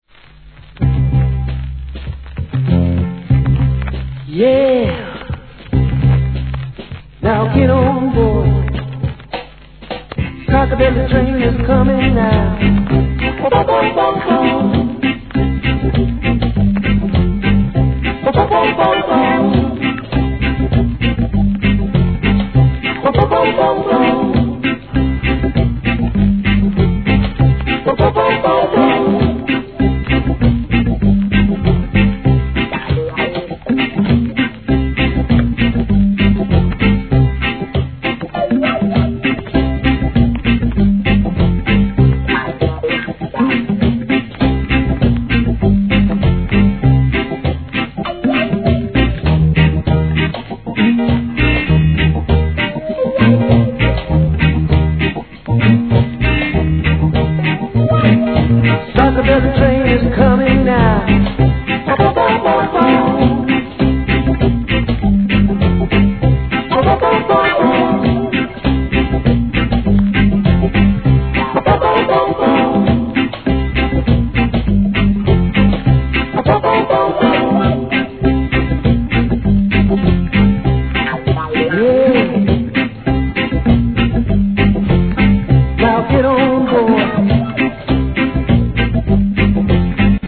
REGGAE
ベースで聴かせるFUNKY REGGAE!!!